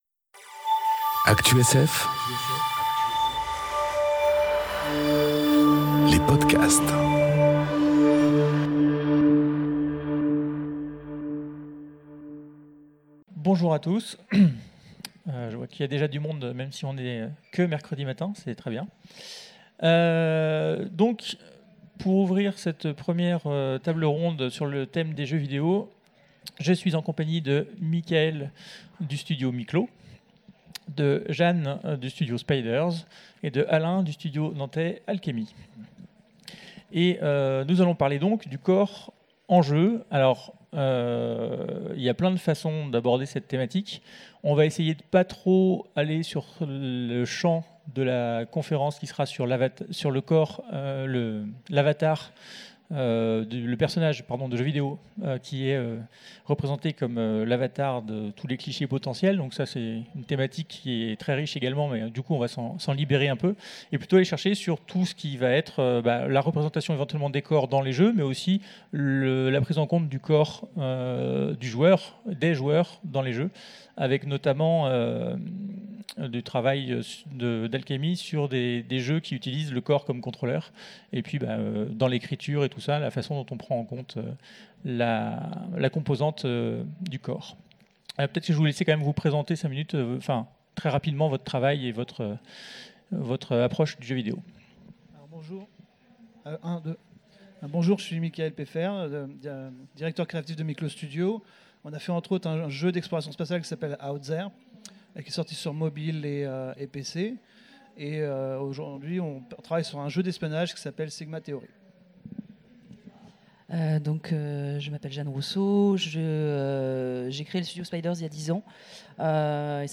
Conférence La place du corps dans les jeux enregistrée aux Utopiales 2018